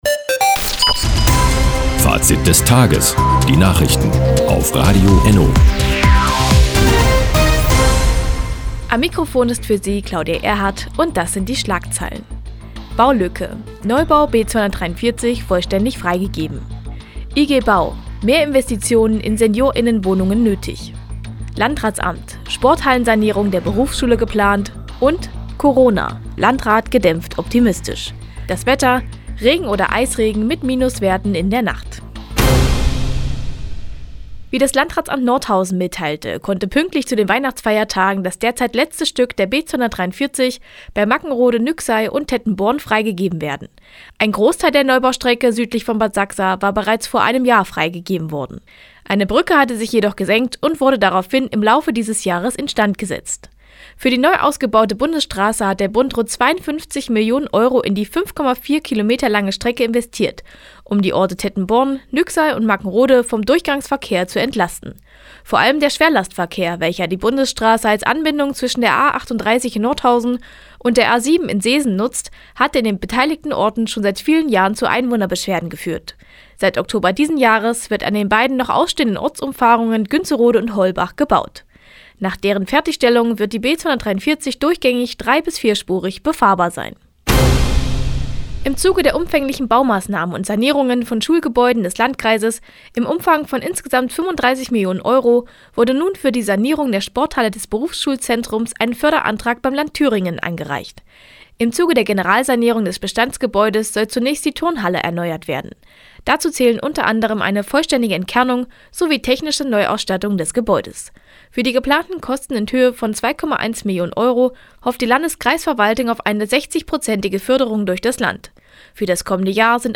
28.12.2020, 16:30 Uhr : Seit Jahren kooperieren die Nordthüringer Onlinezeitung und das Nordhäuser Bürgerradio ENNO. Die tägliche Nachrichtensendung ist jetzt hier zu hören.